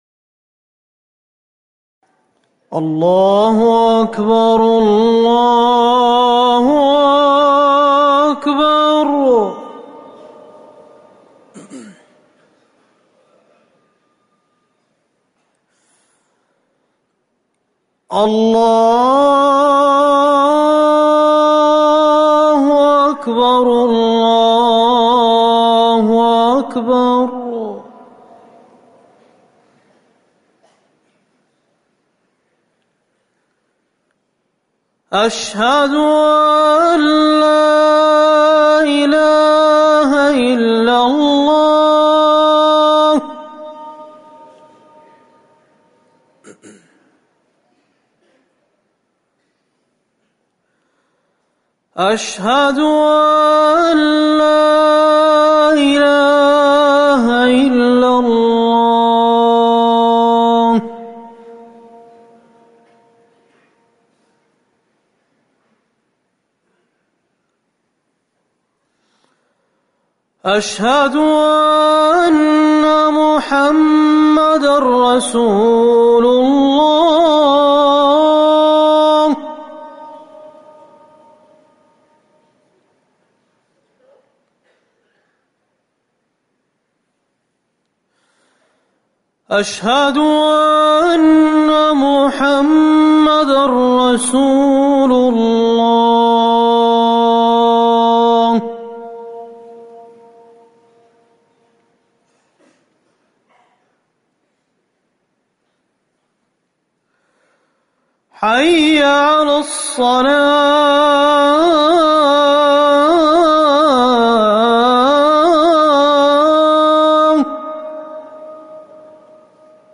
أذان الفجر الثاني
المكان: المسجد النبوي